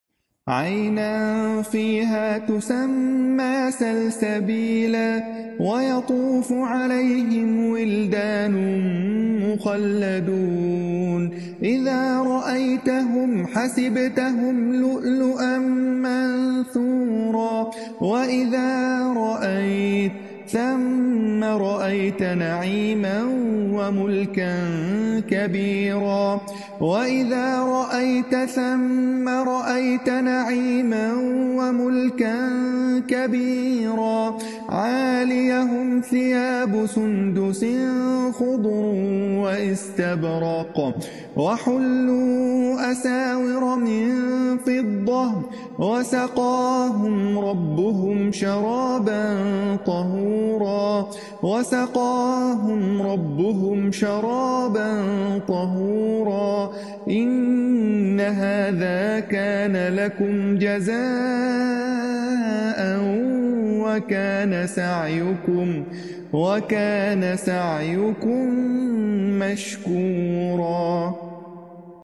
تلاوة خاشعة لقول الله تعالى: sound effects free download